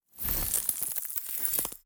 Crystallize_14.wav